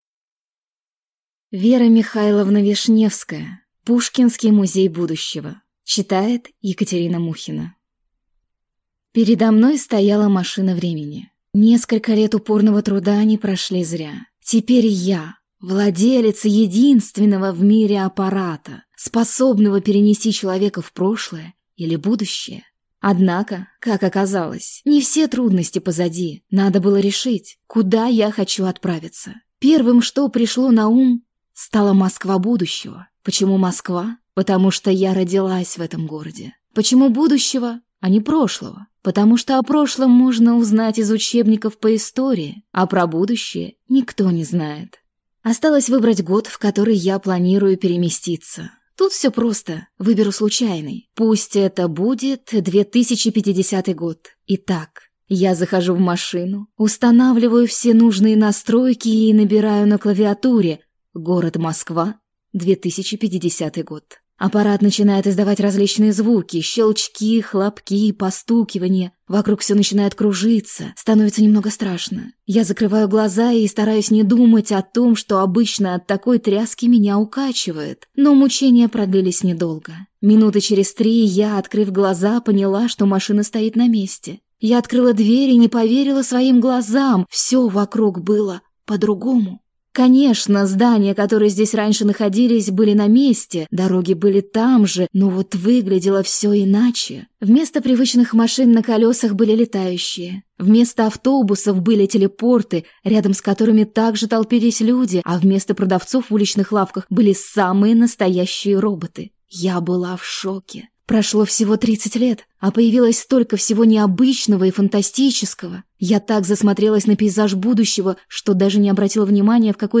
Аудиокнига Пушкинский музей будущего | Библиотека аудиокниг
Прослушать и бесплатно скачать фрагмент аудиокниги